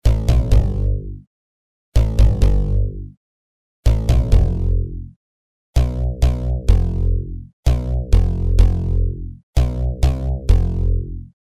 陷阱旋律变奏曲
Tag: 136 bpm Trap Loops Synth Loops 608.83 KB wav Key : Unknown